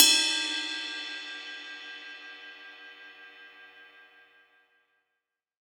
• Ride Cymbal Audio Clip E Key 02.wav
Royality free ride sample tuned to the E note. Loudest frequency: 5351Hz
ride-cymbal-audio-clip-e-key-02-sYb.wav